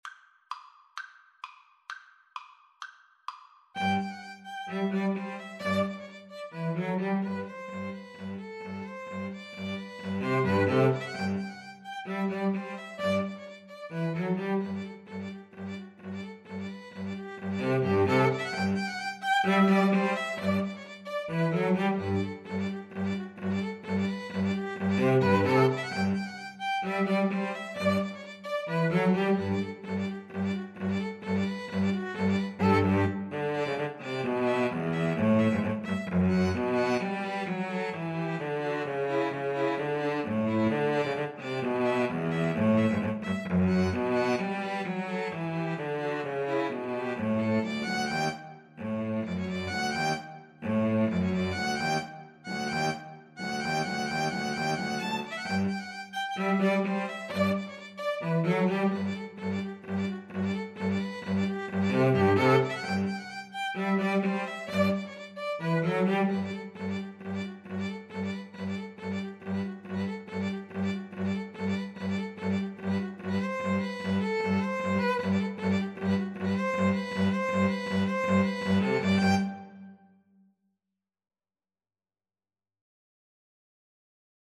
Violin 1Violin 2Cello
2/4 (View more 2/4 Music)
Classical (View more Classical 2-Violins-Cello Music)